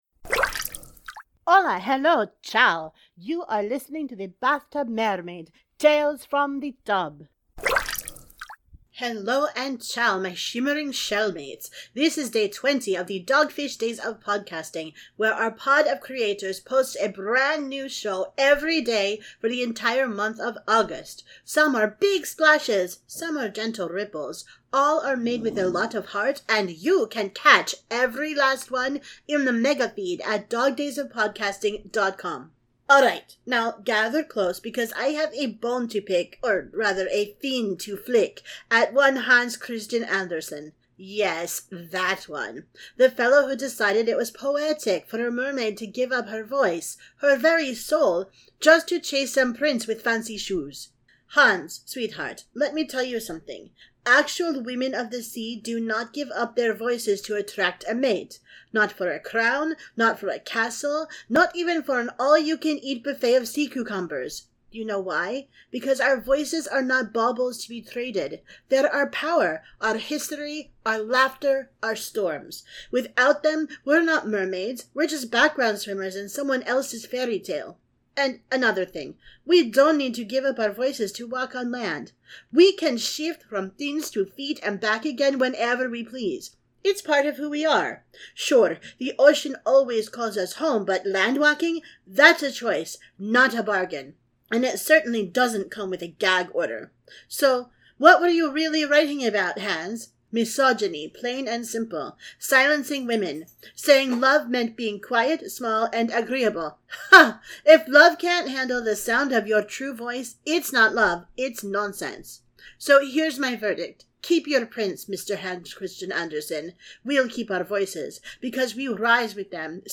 • Sound Effects are from Freesound.